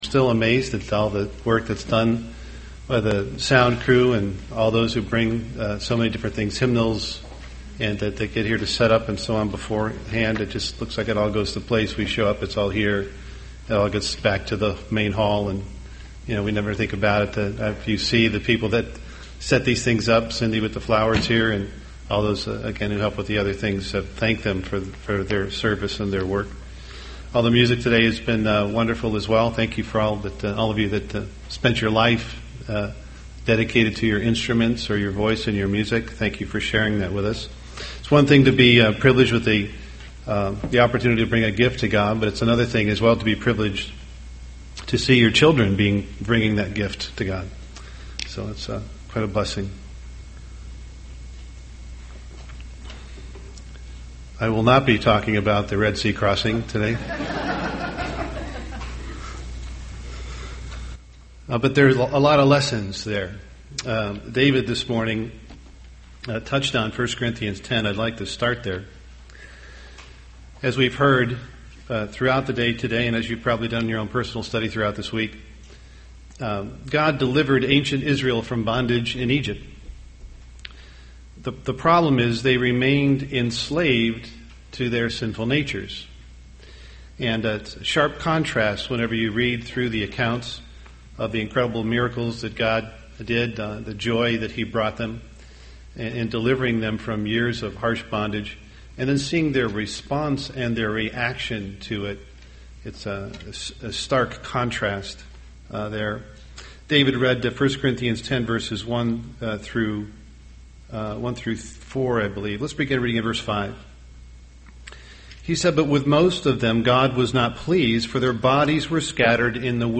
UCG Sermon sin Unleavened Bread Overcoming Sin Studying the bible?